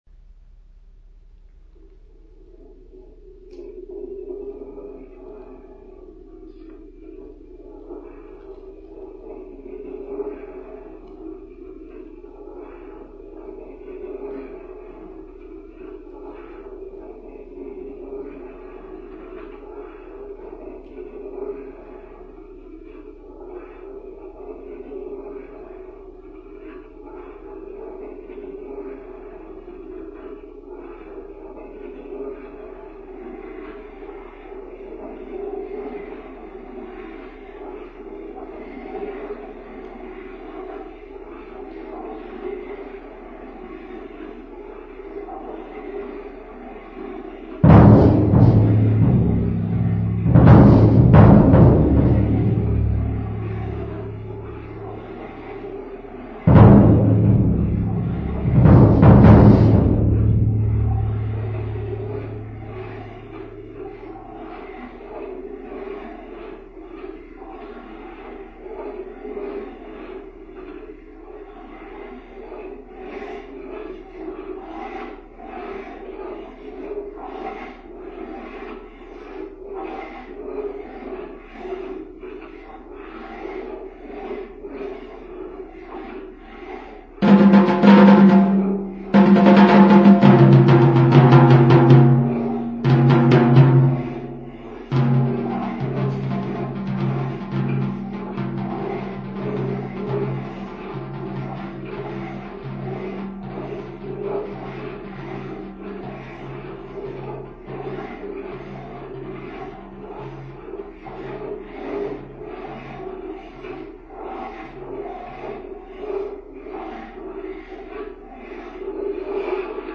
for Amplified Doors and Percussion